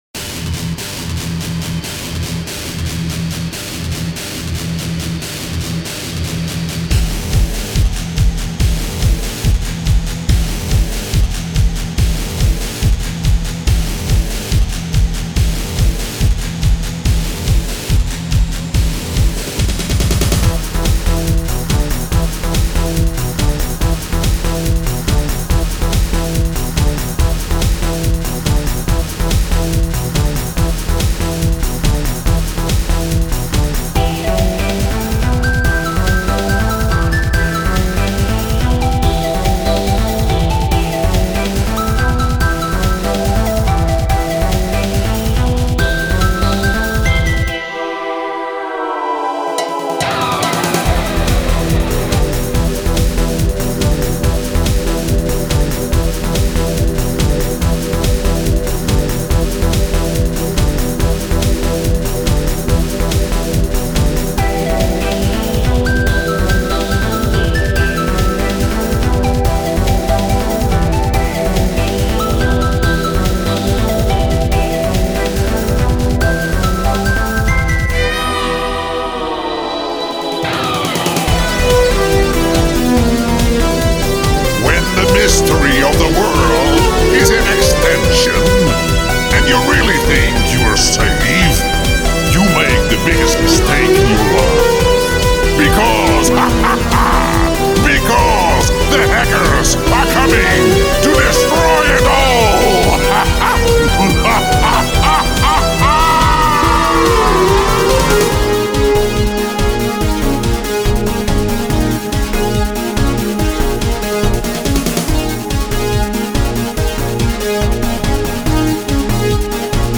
SchouderCom - Zonder zang
Lied-6-Hack-song-instrumentaal-.mp3